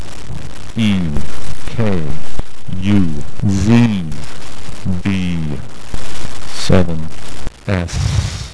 Cosmologia quantistica: intervista